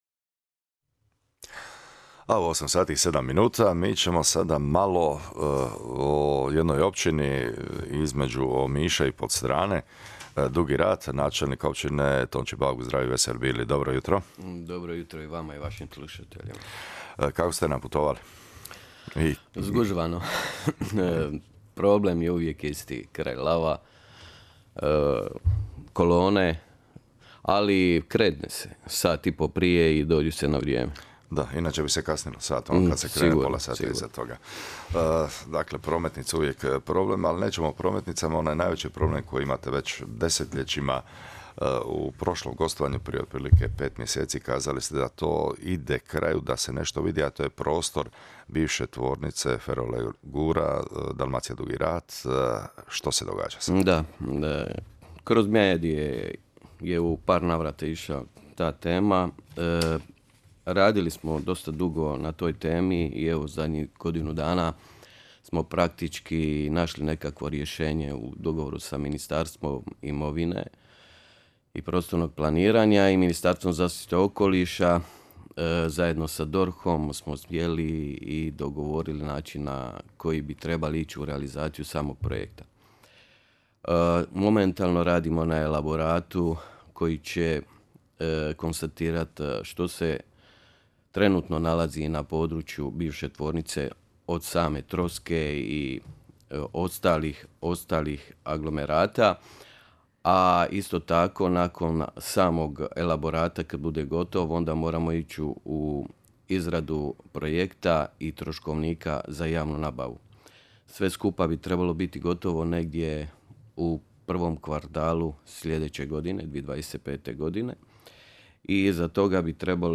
Načelnik Bauk gostovao je na radio Splitu. Tijekom razgovora odgovarao je na aktualna pitanja i najavio važne projekte za našu općinu.